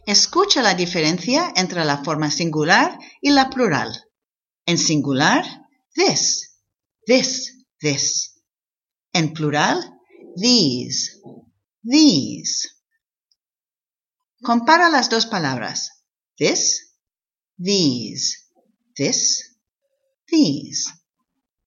La palabra this contine solo 3 sonidos, pero 2 de ellos son difíciles de pronunicar  para los hispanoparlantes: el “th” inicial y la vocal corta i.
These contiene 3 sonidos: la vocal es mucho más larga y la s final es sonora, se pronuncia como una z inglesa.
Escucha la diferencia entre this y these.